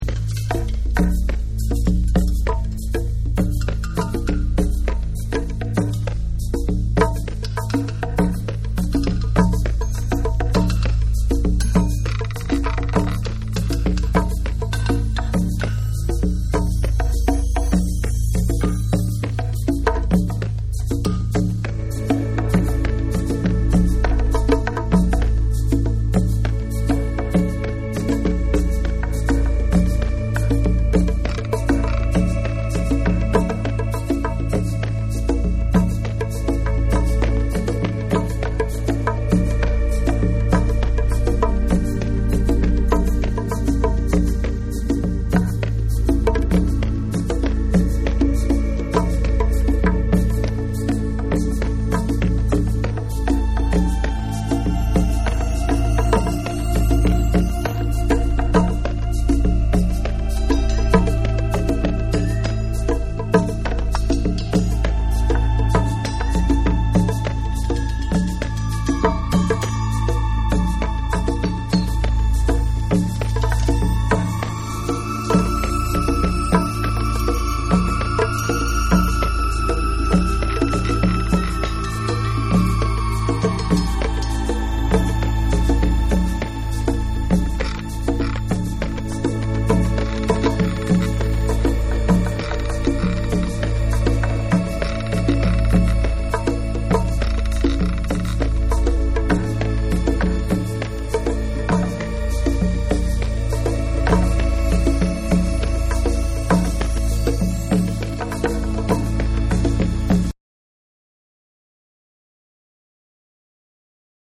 TECHNO & HOUSE / ORGANIC GROOVE / NEW RELEASE(新譜)